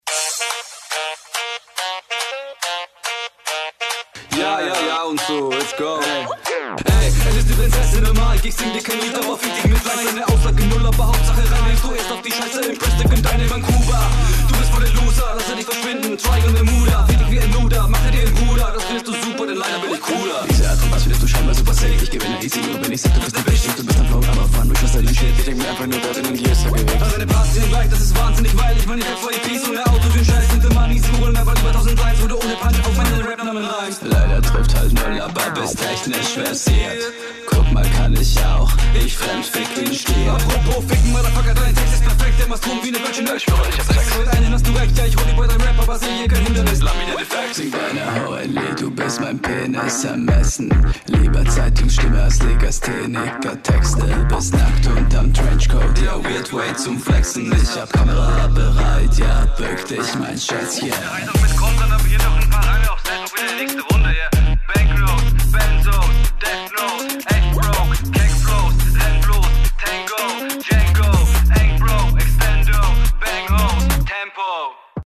ich versteh nichts, was ist diese mische? die doubles machen mich fertig, das könnte so …